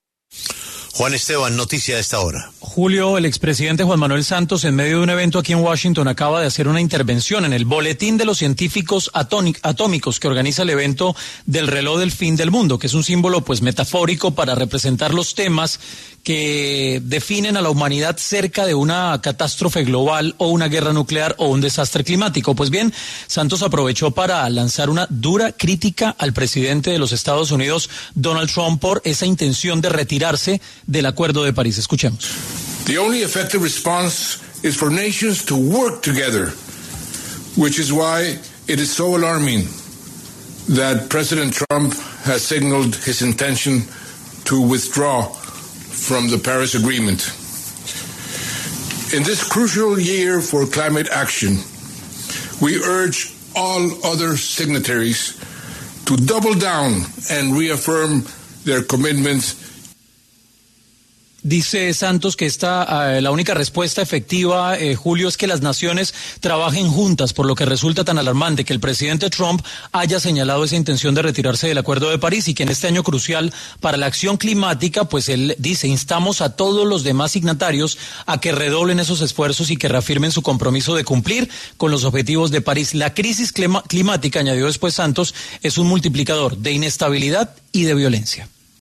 En medio del evento del Reloj del Fin del Mundo, un evento en el que estuvo el expresidente Juan Manuel Santos, el exmandatario lanzó una fuerte crítica contra el presidente de Estados Unidos, Donald Trump, por su intención de retirarse del Acuerdo de París.